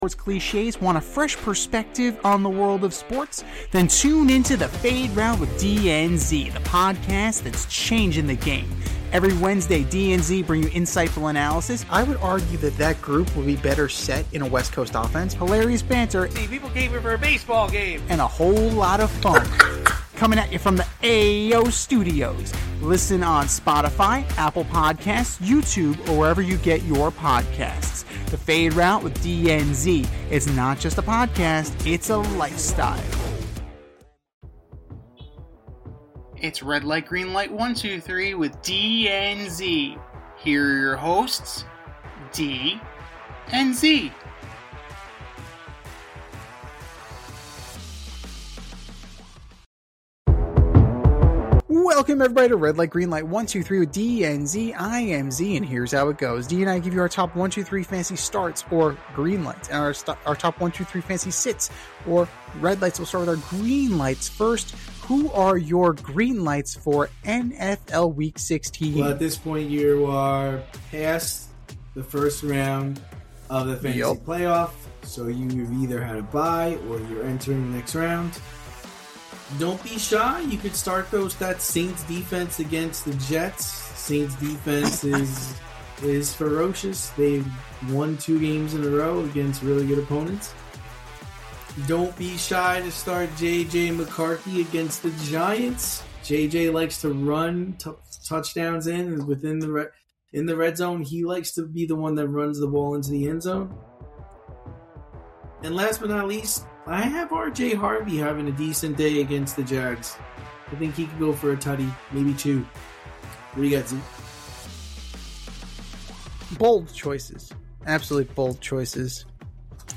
two veteran sports aficionados and lifelong friends, as they dissect the week’s top stories with wit and a touch of New York flair.